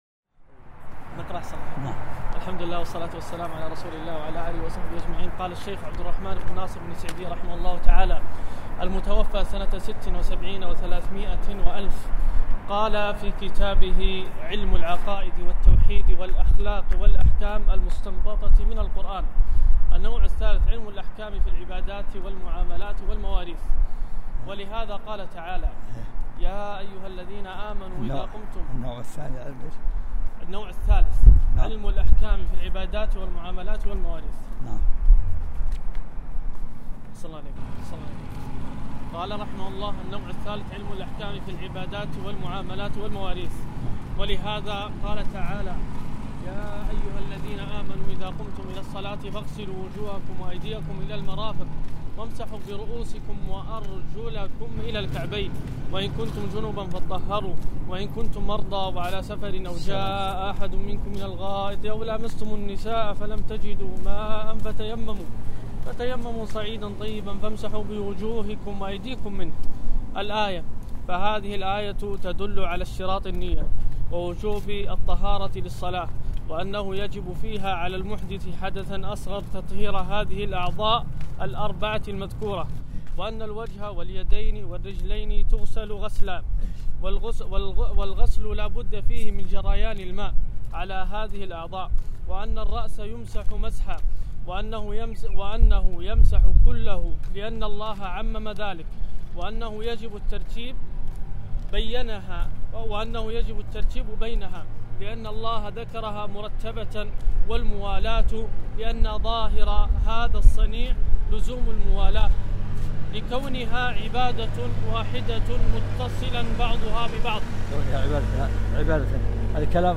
الدرس السادس - فتح الرحيم الملك العلام في العقائد